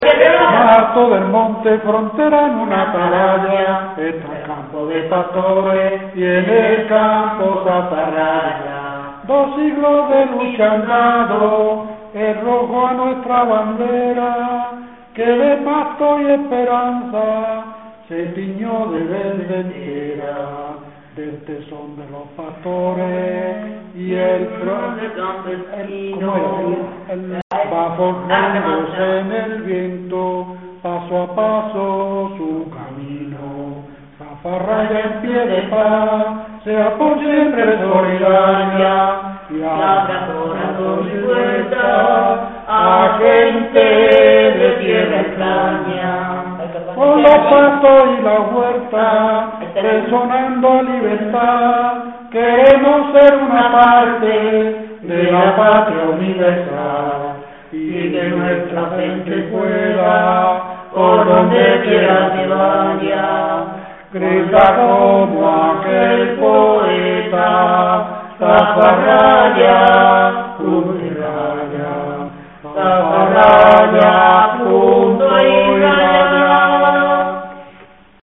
Zafarraya (Granada) Icono con lupa
Secciones - Biblioteca de Voces - Cultura oral